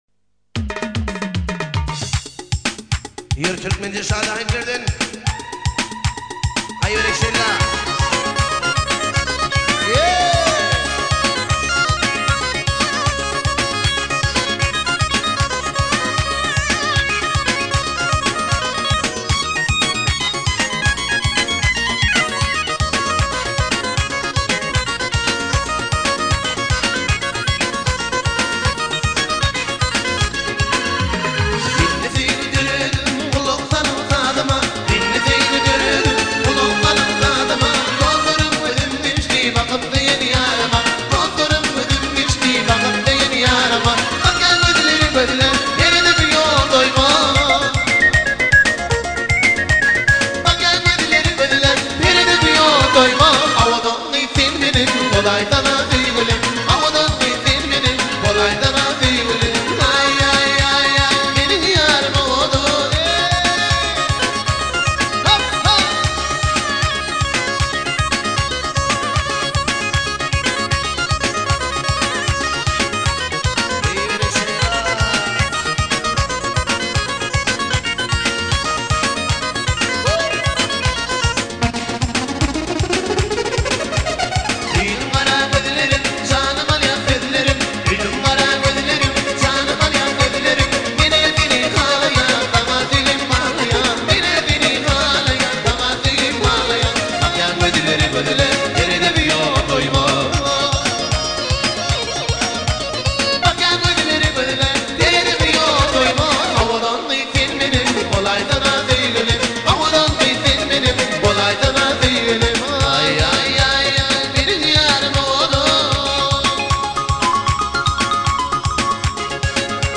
آهنگ شاد ترکمنی جدید 1402 بیس دار